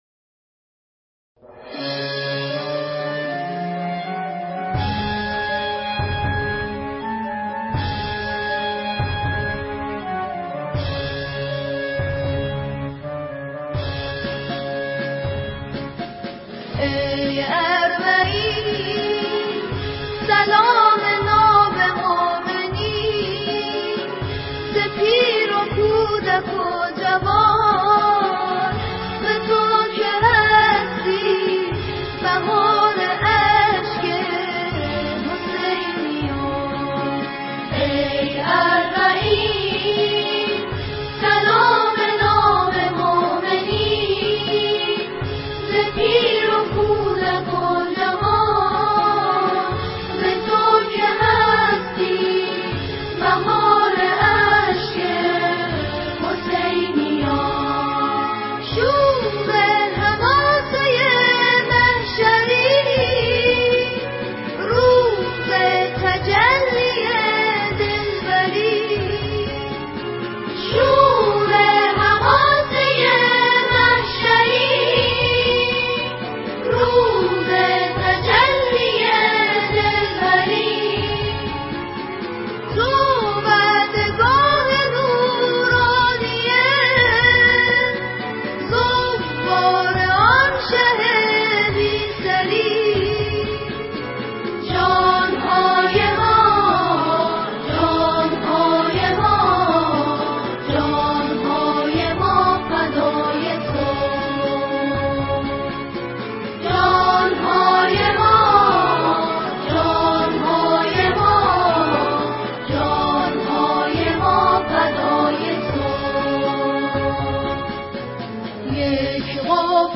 با لحنی جان‌سوز و حماسی